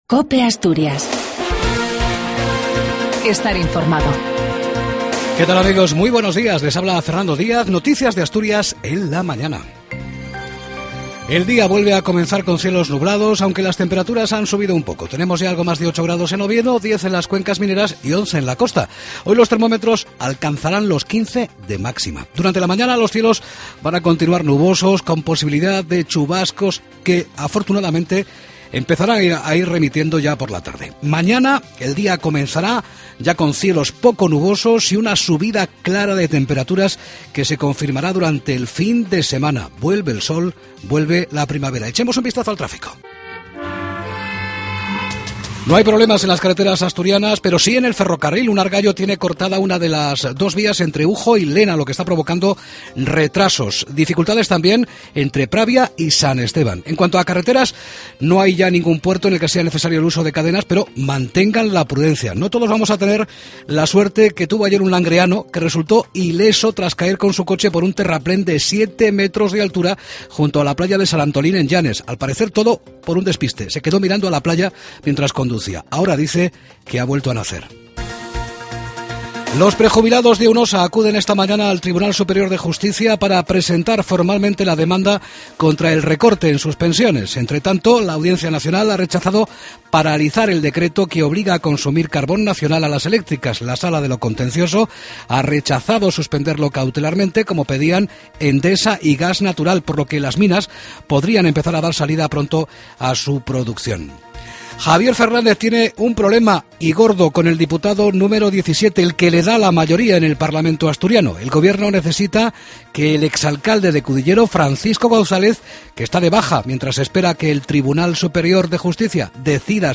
AUDIO: LAS NOTICIAS DE ASTURIAS A PRIMERA HORA DE LA MAÑANA.